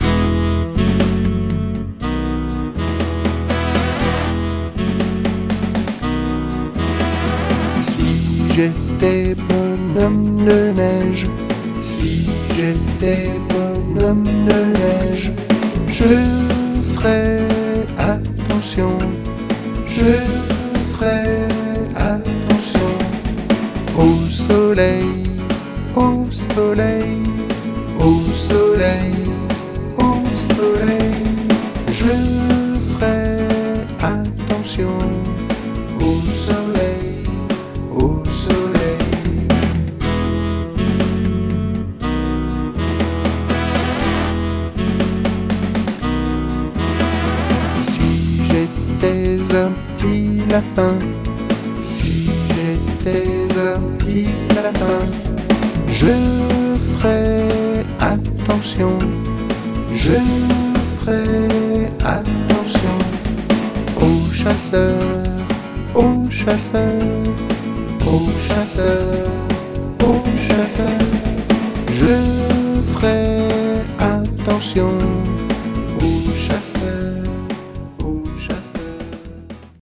Version vocale